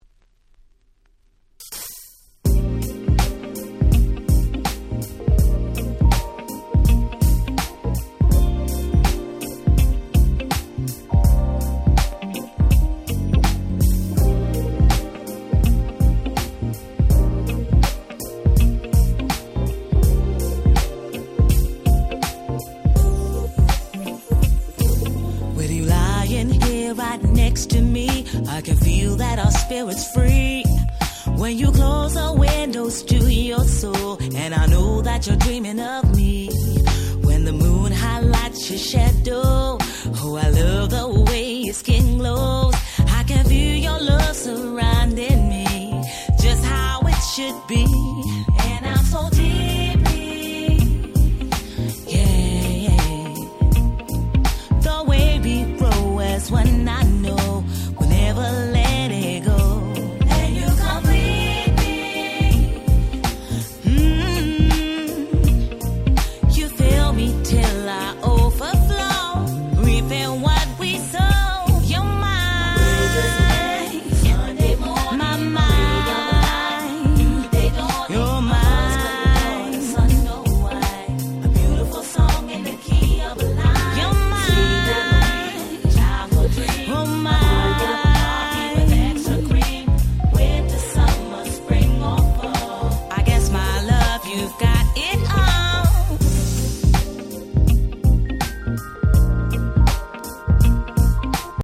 02' Nice UK Soul !!